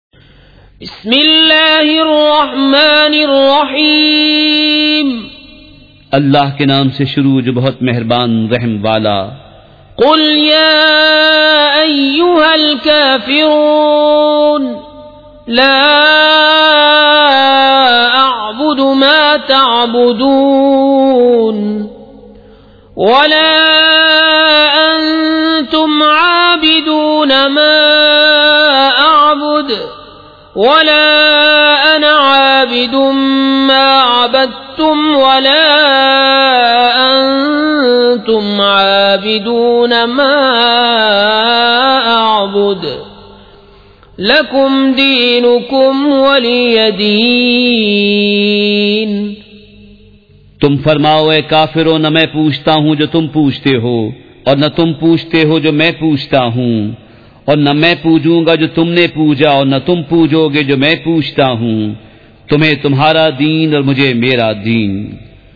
تلاوت
surah-al-kafiroon-with-urdu-translation.mp3